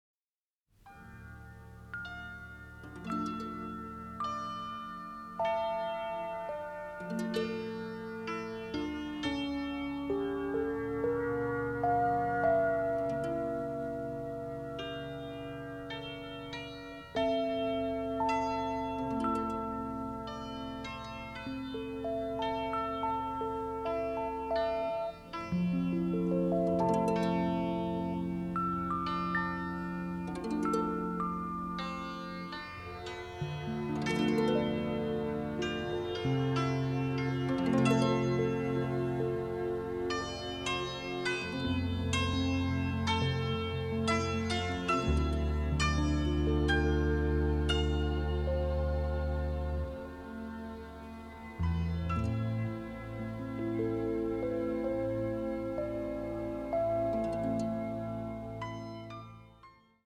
deeply delicate score